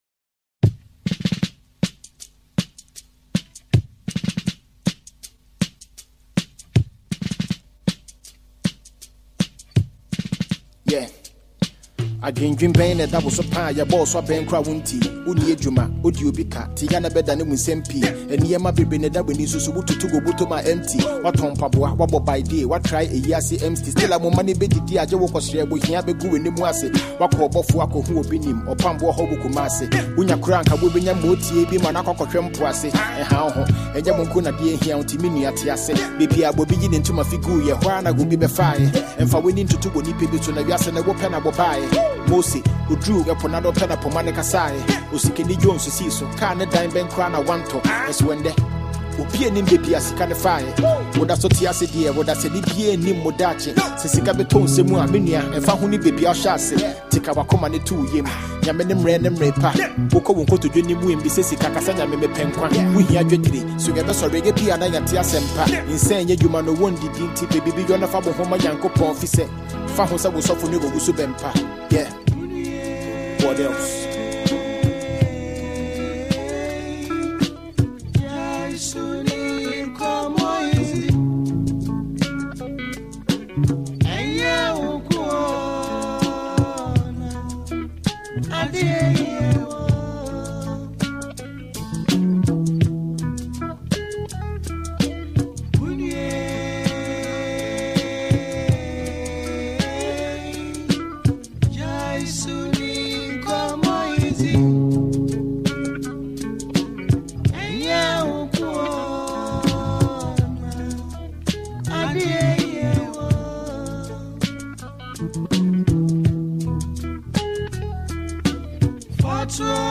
Stream and download this cover below.